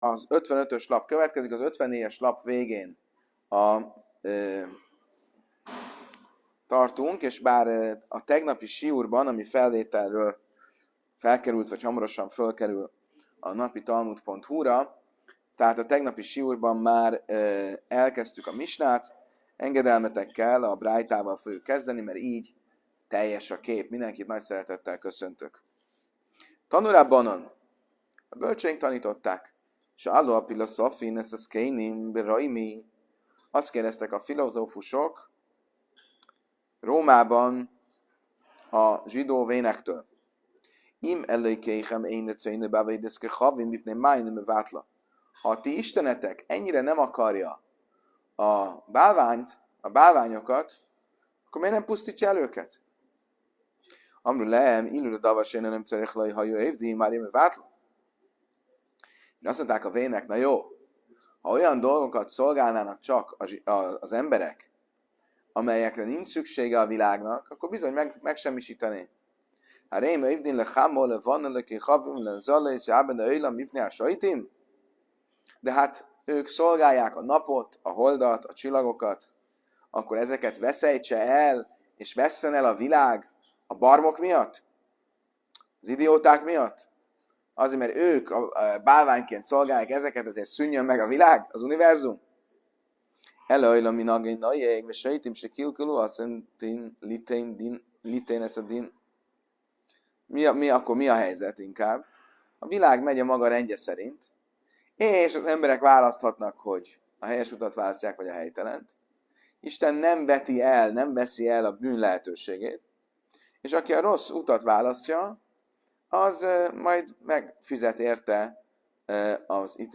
előadása